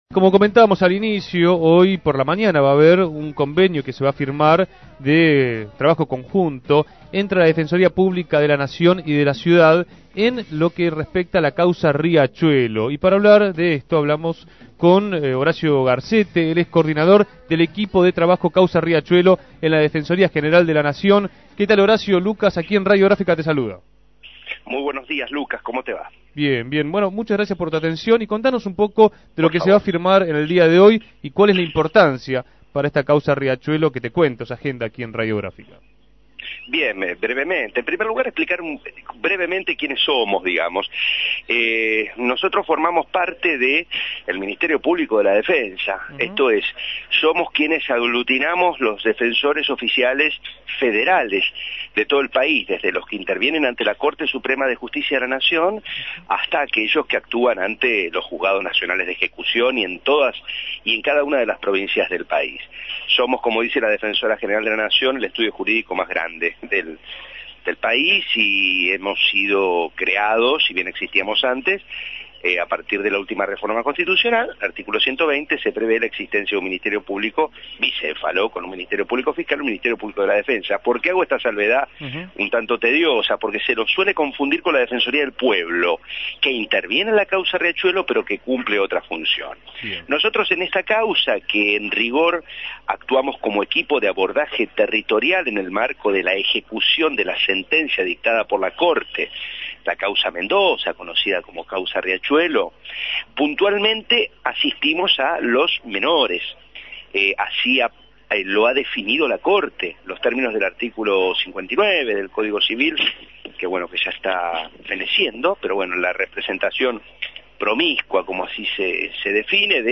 dialogó esta mañana